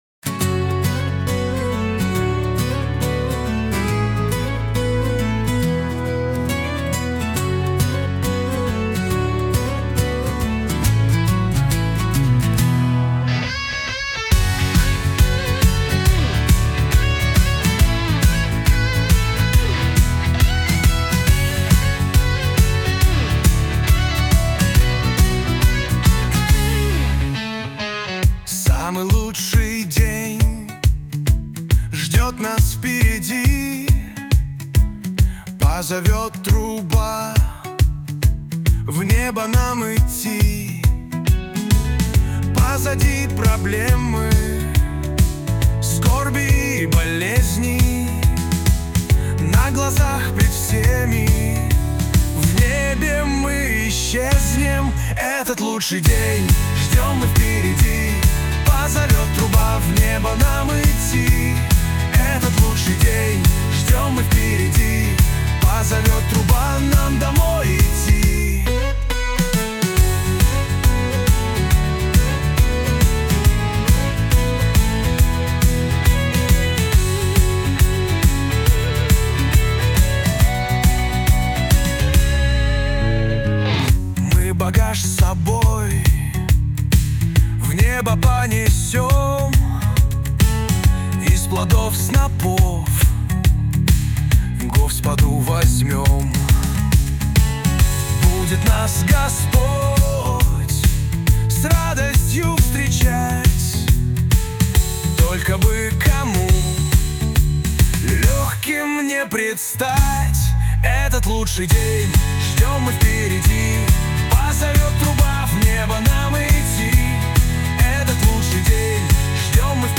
песня ai
351 просмотр 1340 прослушиваний 91 скачиваний BPM: 138